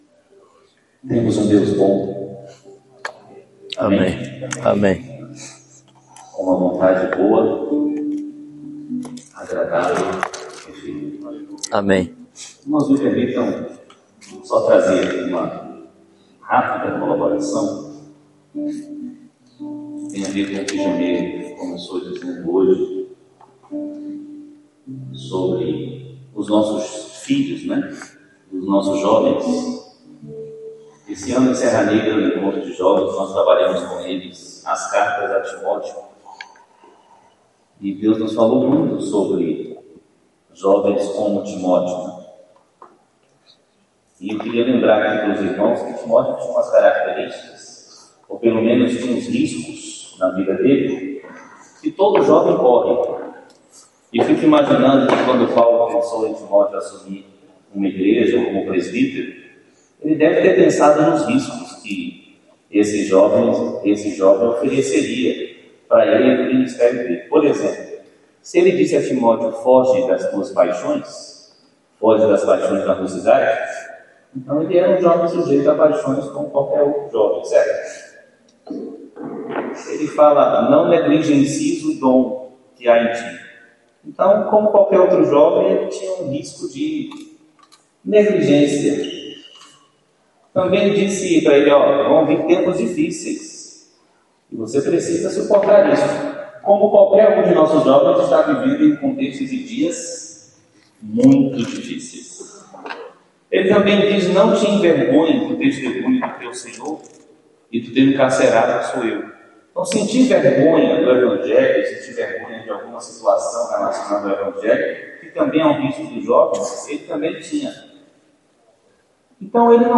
Encontro de Pastores